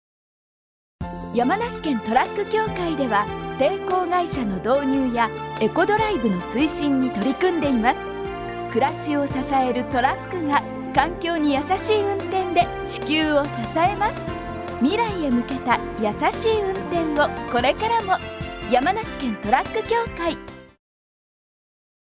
ラジオコマーシャル